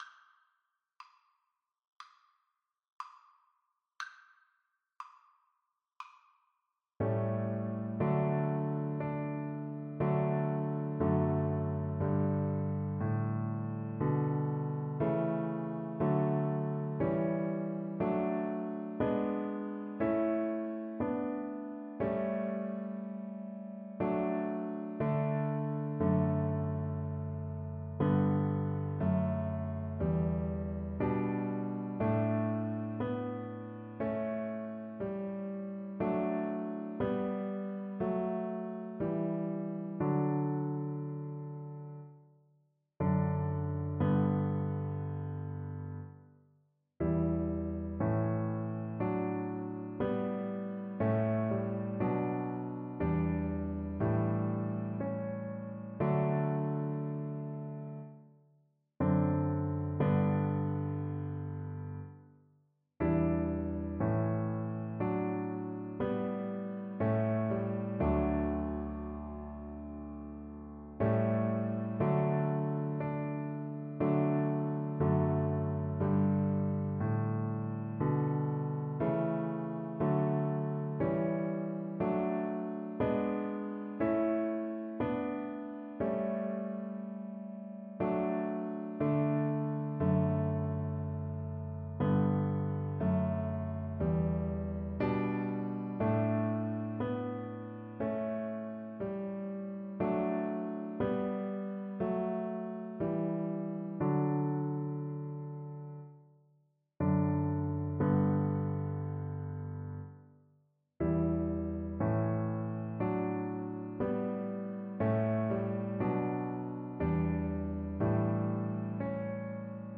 Traditional Trad. Sing Hosanna (Give Me Joy in My Heart) Cello version
Christian Christian Cello Sheet Music Sing Hosanna (Give Me Joy in My Heart)
Cello
4/4 (View more 4/4 Music)
B3-Db5
D major (Sounding Pitch) (View more D major Music for Cello )
Traditional (View more Traditional Cello Music)